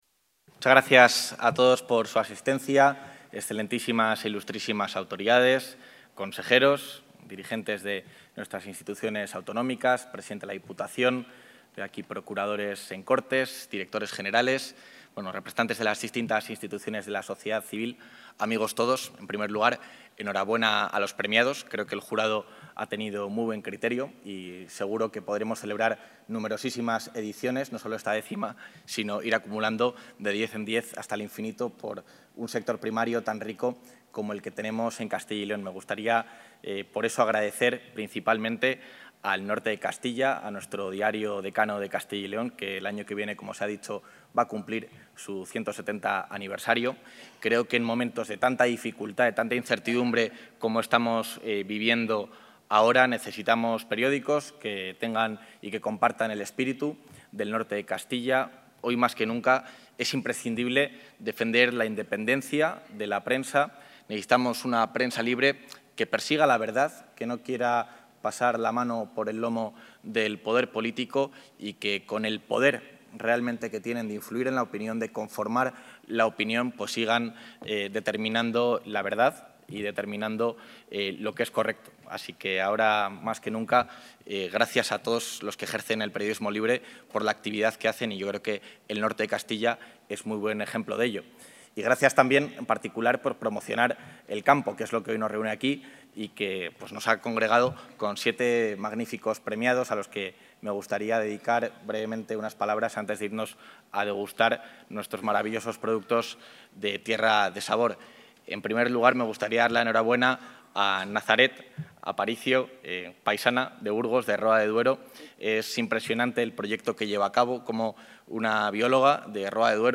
Intervención del vicepresidente.
Juan García-Gallardo ha clausurado esta tarde la X Edición de los Premios del Campo de 'El Norte de Castilla'